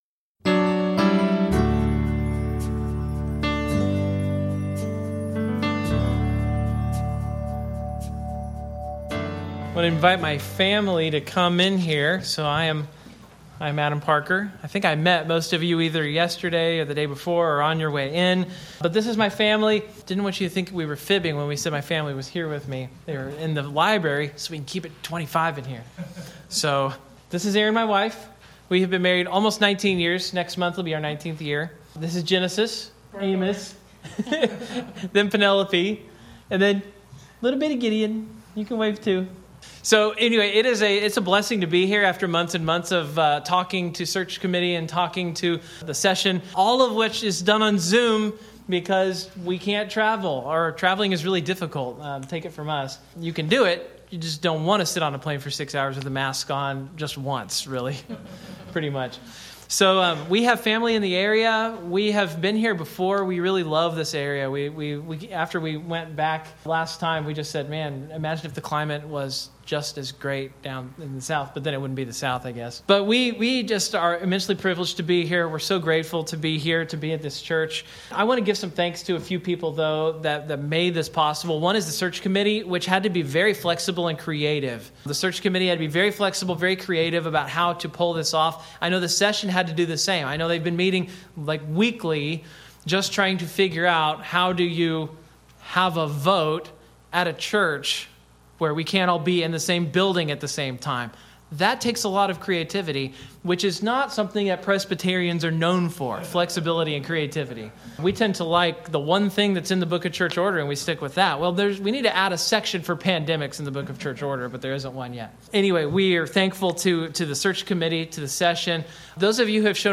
Audio and video of the sermon are now posted.